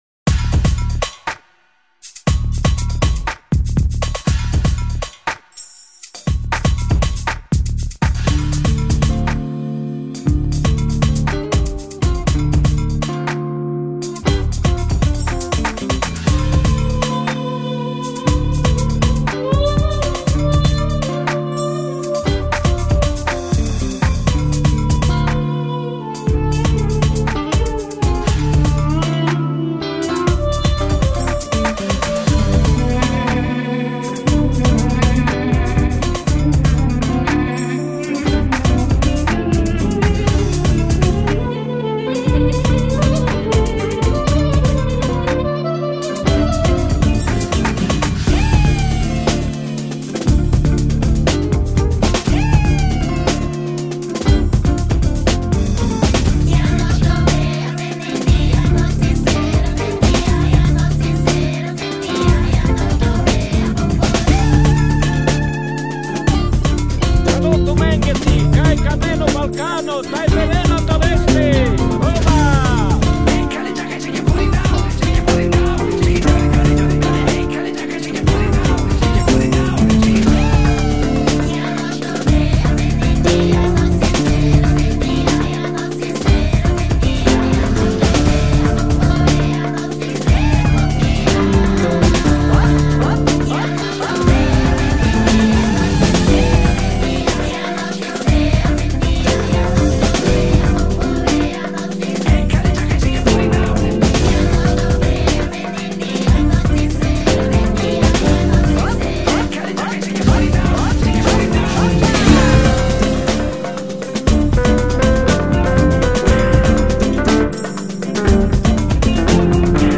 El. and Ac. Guitars, Keyboards, Bass and Drum Programming
Bouzouki ( Loops )
Violin ( Loops )
Balkan Vocals ( Loops )
Balkan Brass ( Loops )
Sax ( Loops )